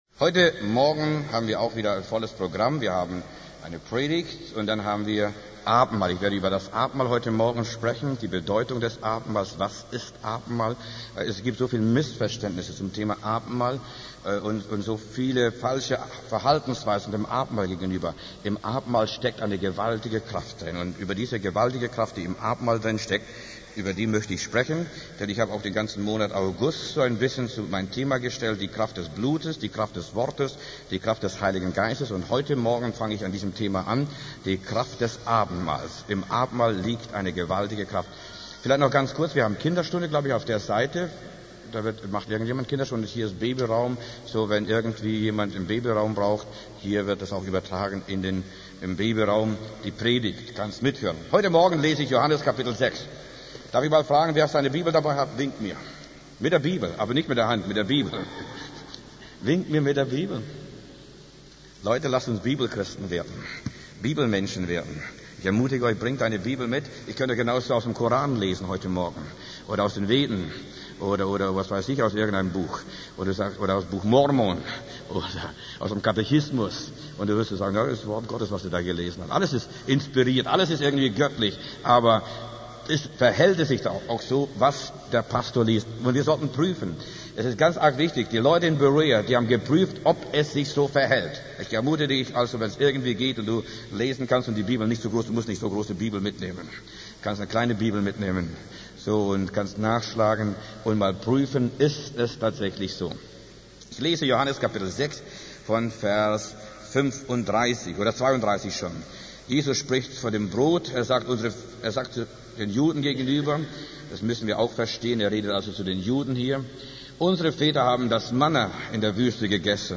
Predigten der Freien Nazarethkirche e.V. in Berlin